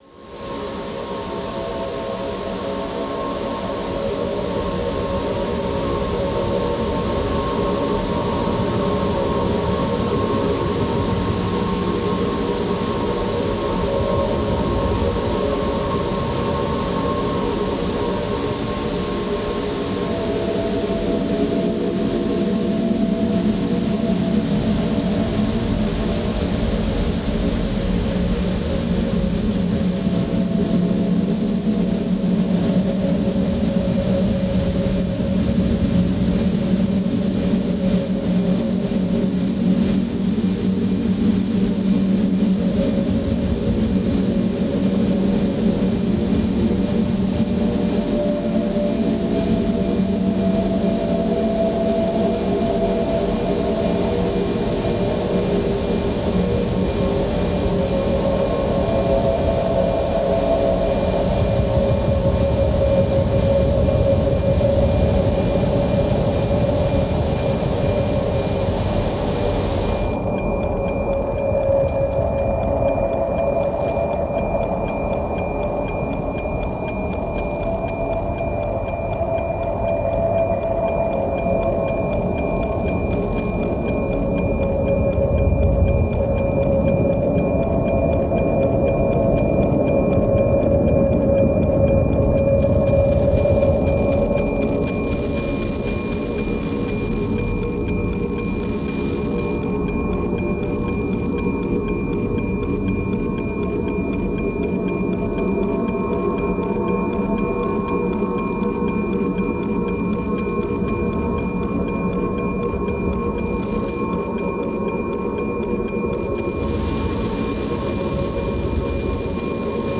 The weather satellite NOAA-19 orbits over central Germany on a balmy day in July, its analog signal translated by a DIY antenna and relayed to an unknown number of listeners.
These satellite séances move through radio environments filled with many other meetings. A ghostly voice emerges from a sea of white noise. Magnetic fields and moving particles produce the ‘electromagnetic dawn chorus’, a phenomenon long compared to the sound of birds greeting the rising sun.